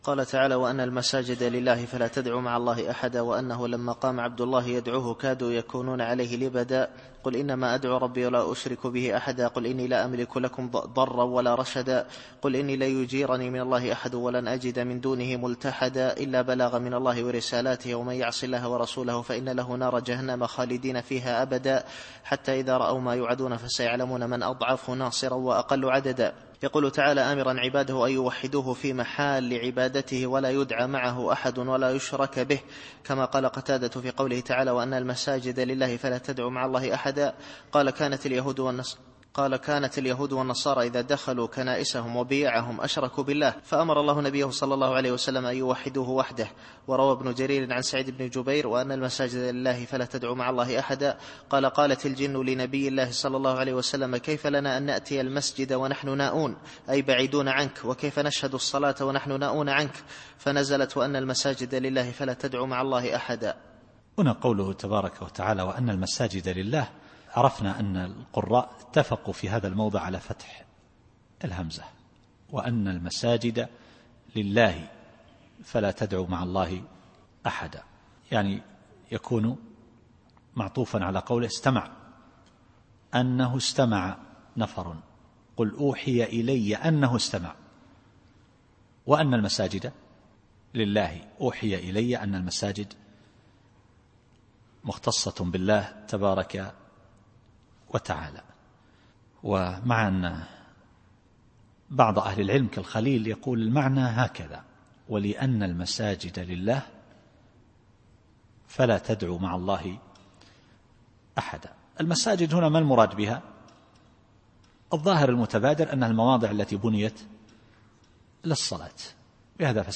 التفسير الصوتي [الجن / 18]